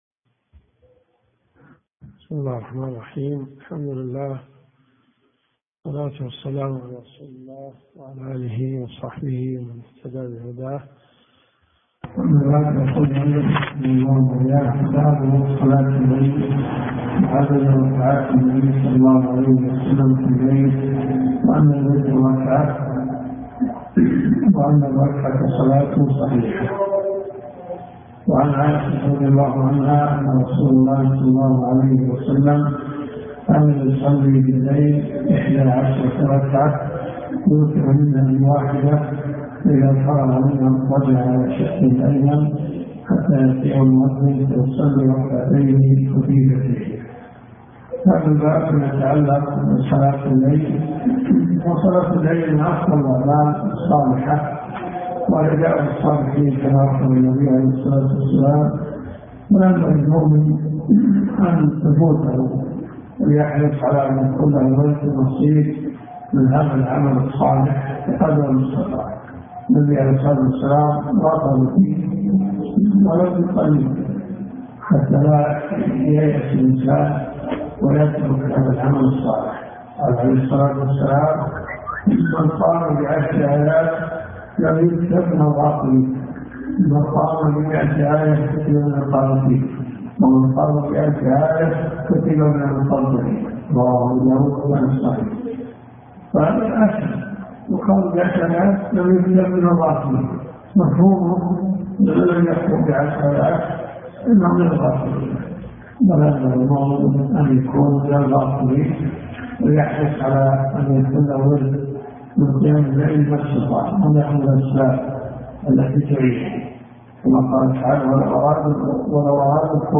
دروس صوتيه ومرئية تقام في جامع الحمدان بالرياض